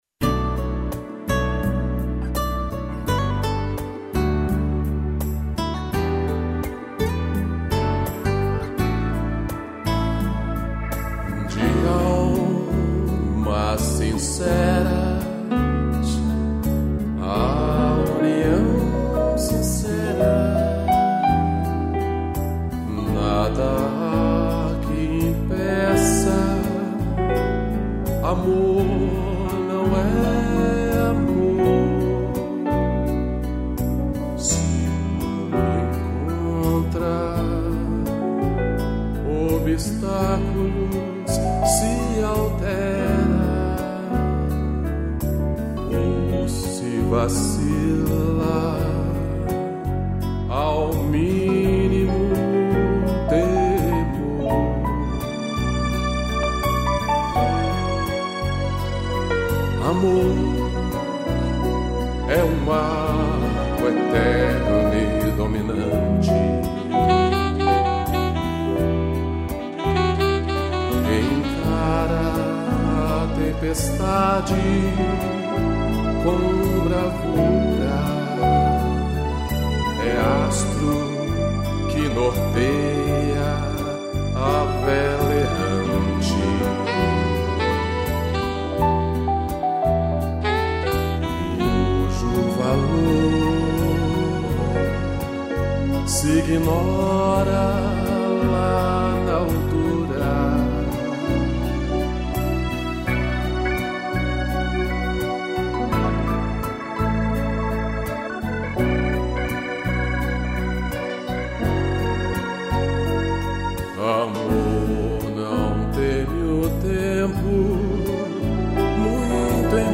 piano, sax e string